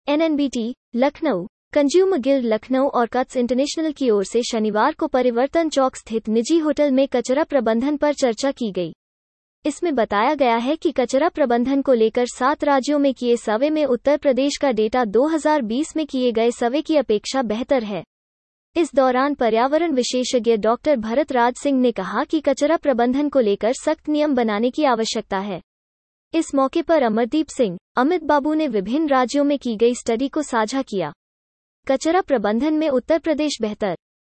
Voice Reading .